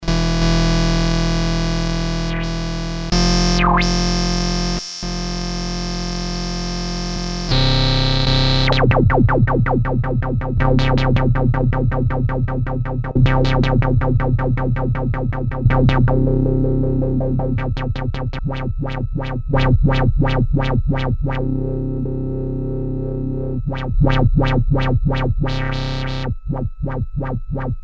Portable analog synthesizer (but digital oscillator) similar to Electro harmonix Mini-Synthesizer or EMS synthi.
Class: Synthesizer
Polyphony: 1 note